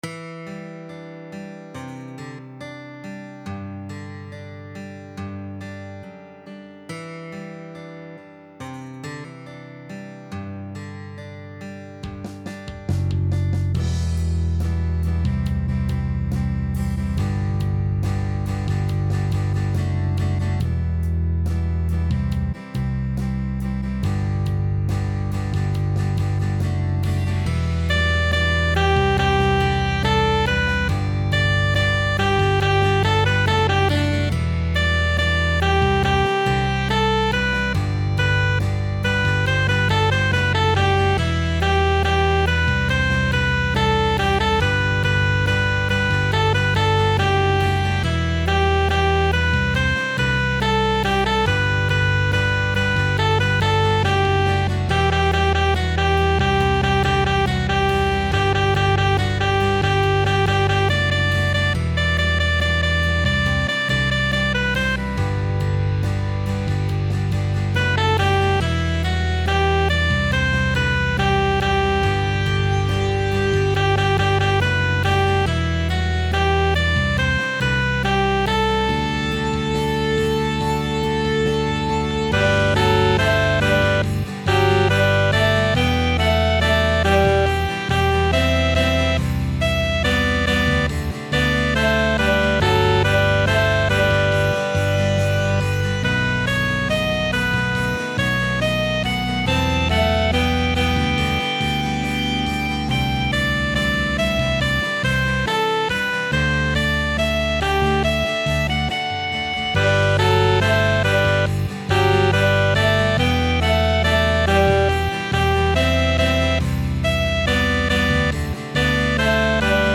谱内音轨：主旋律、电吉他、节奏吉他、分解吉他、贝斯、鼓
曲谱类型：乐队总谱